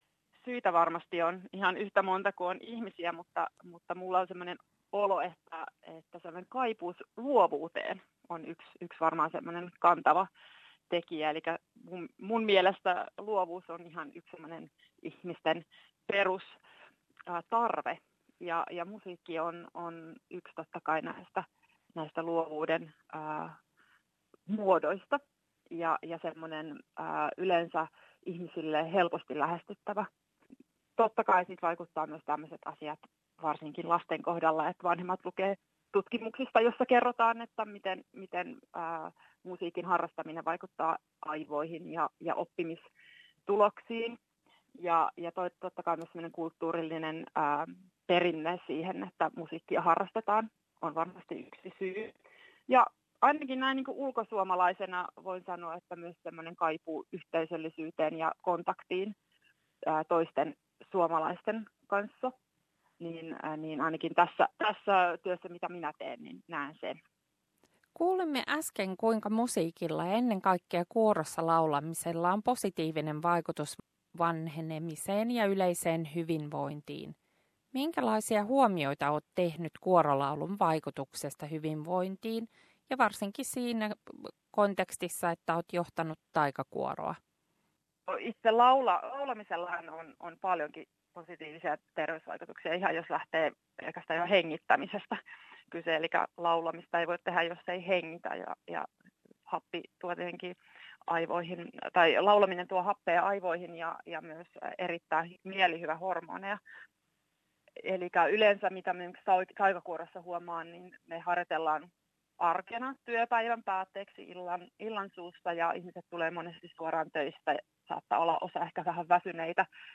Haastattelun lopuksi kuulemme suomalaisen kansanlaulun Ai ai sorja sinisilmäpoika Taika kuoron esittämänä.